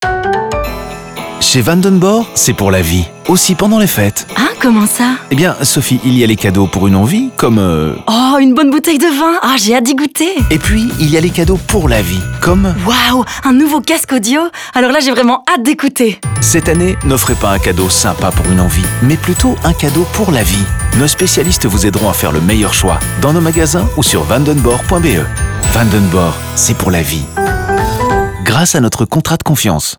Plusieurs spots radio et une série de vidéos en ligne font également la même comparaison de manière ludique.
Radio